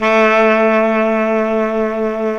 Index of /90_sSampleCDs/Roland LCDP07 Super Sax/SAX_Baritone Sax/SAX_40s Baritone
SAX B.SAX 09.wav